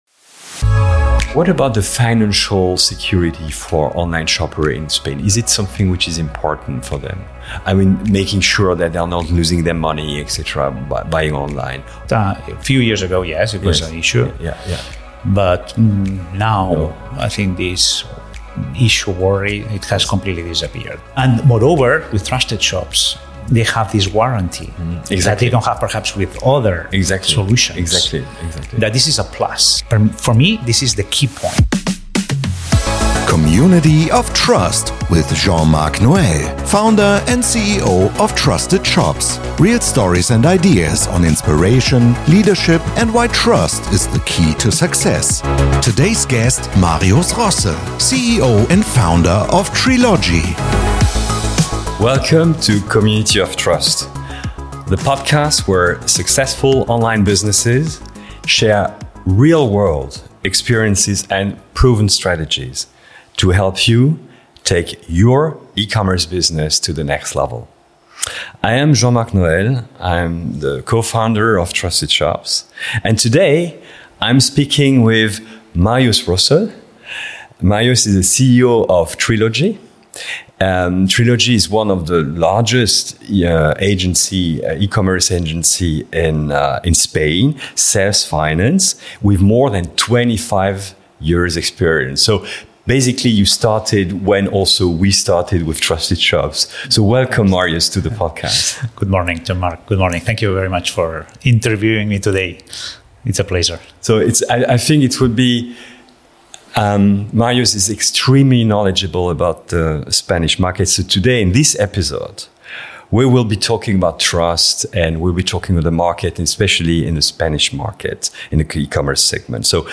In an open and honest conversation, he shares his key learnings: building a business without established role models, navigating Spain’s strong regional differences, and deciding how retailers should approach AI today. One thing becomes clear: trust is not created by tools or trends, but through consistency, high service quality, and genuine closeness to customers – both online and offline.